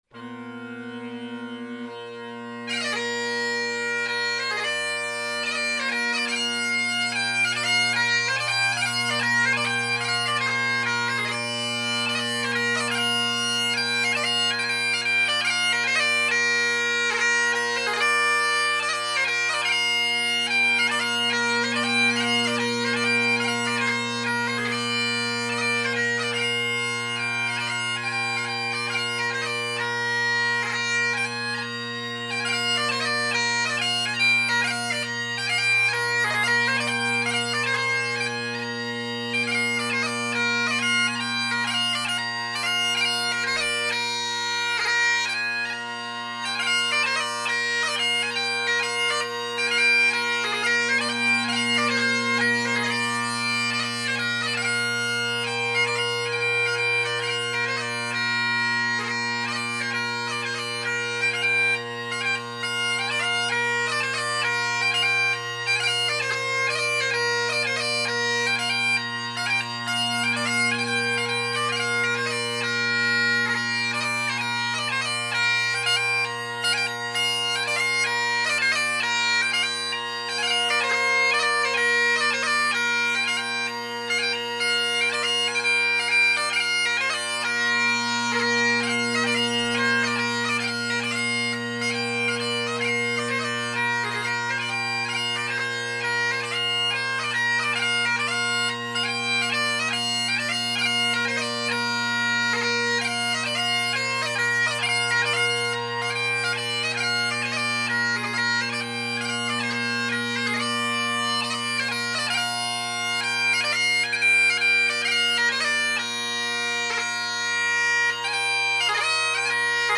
John MacColl’s March to Kilbowie Cottage, Clachnaharry, & Roderick MacDonald – an MSR I’ve played for a while now. Attacked Roderick a little too tight and got a squeal :-|